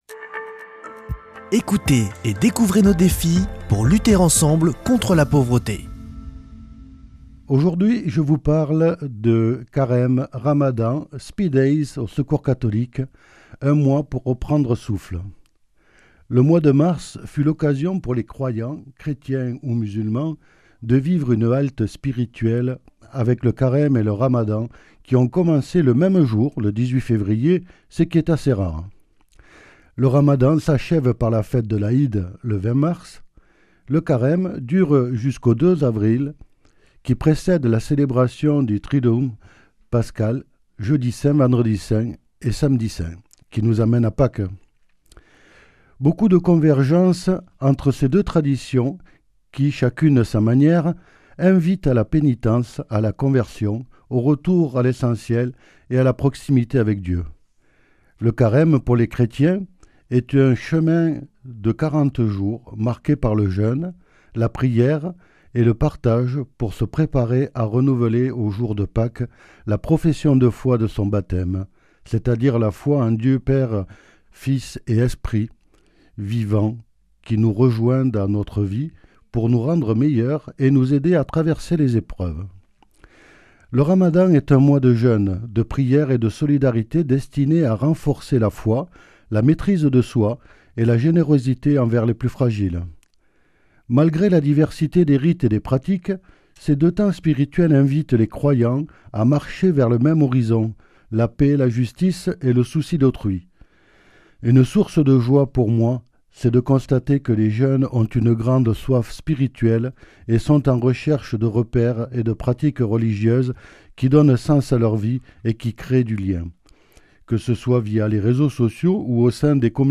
lundi 6 avril 2026 Chronique du Secours Catholique Durée 3 min